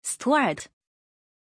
Aussprache von Stuart
pronunciation-stuart-zh.mp3